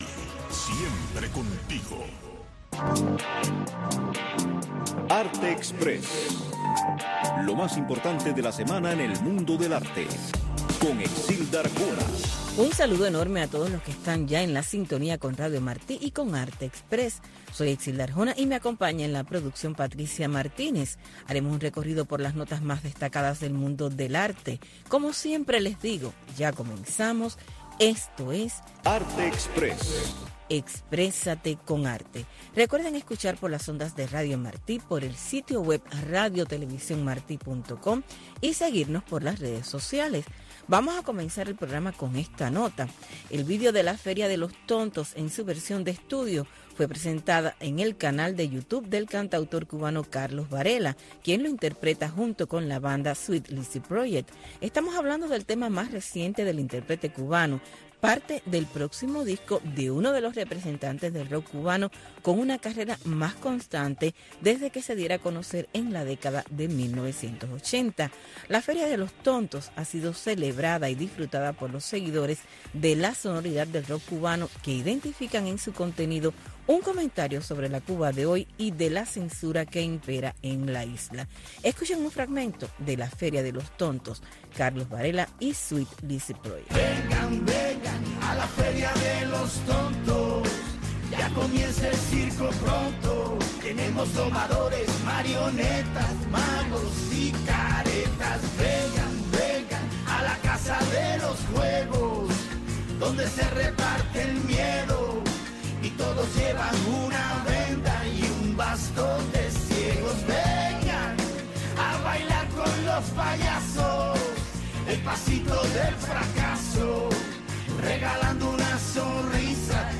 Arte Express, una revista informativa - cultural con noticias, eventos, blogs cubanos, segmentos varios, efemérides, música y un resumen de lo más importante de la semana en el mundo del arte.